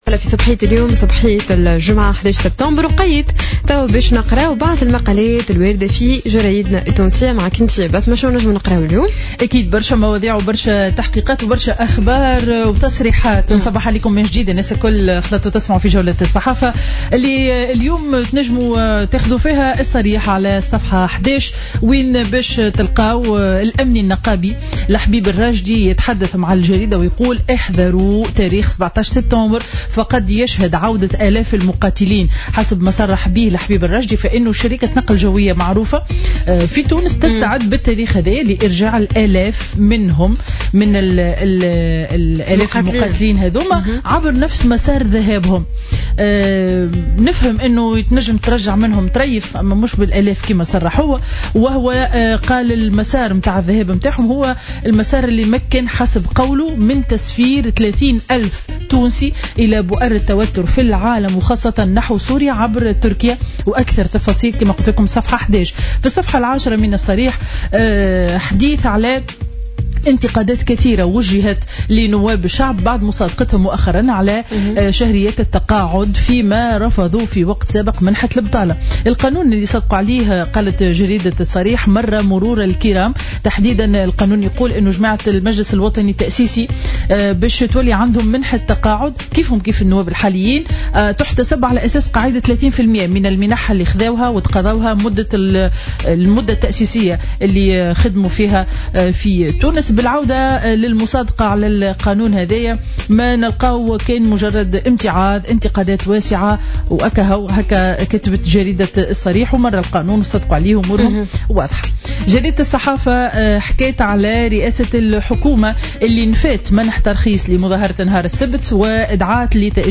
Revue de presse du vendredi 11septembre 2015